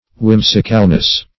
Search Result for " whimsicalness" : The Collaborative International Dictionary of English v.0.48: Whimsicalness \Whim"si*cal*ness\, n. The quality or state of being whimsical; freakishness; whimsical disposition.
whimsicalness.mp3